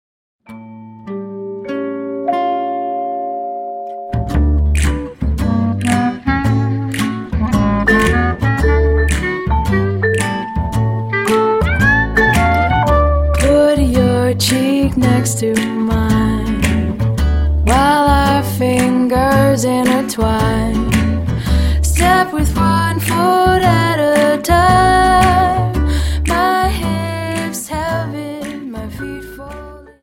Dance: Slowfox 29 Song